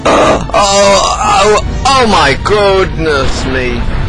Epic noise - Oh oh oh my goodness me
Category: Sound FX   Right: Personal